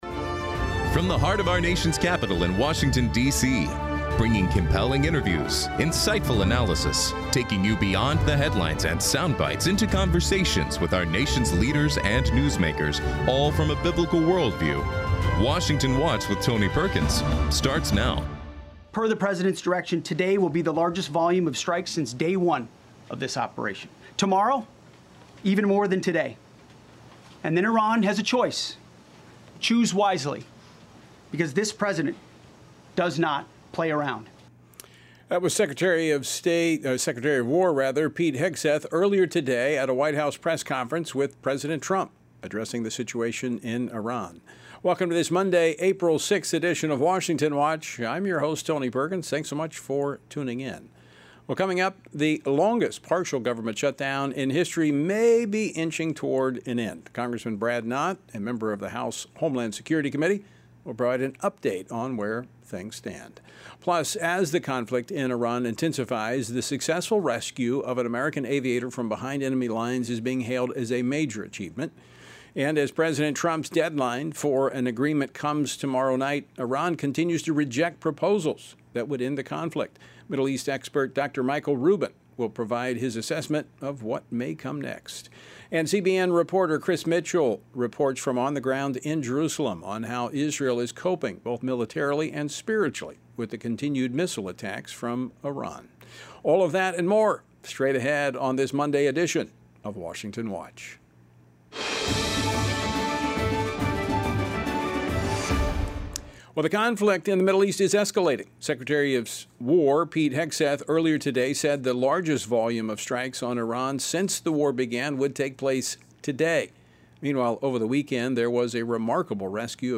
Hard hitting talk radio never has been and never will be supported by the main stream in America! Liberty News Radio is taking on the main stream press like never before!